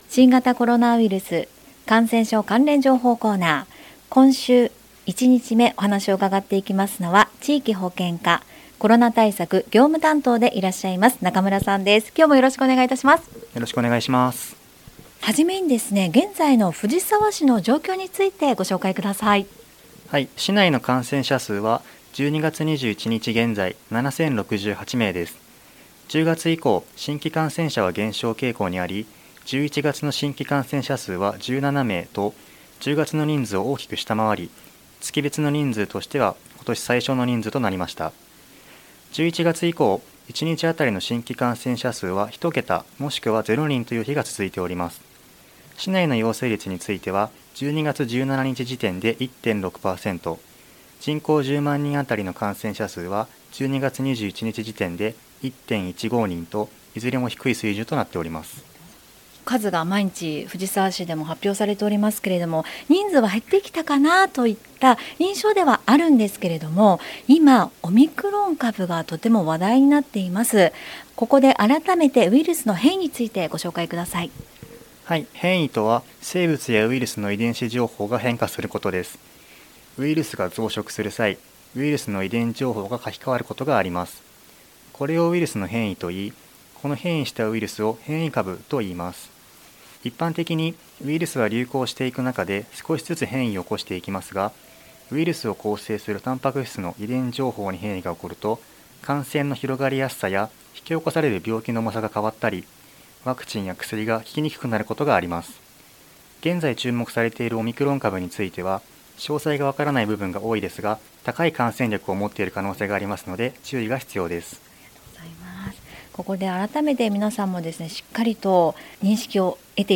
令和3年度に市の広報番組ハミングふじさわで放送された「新型コロナウイルス関連情報」のアーカイブを音声にてご紹介いたします。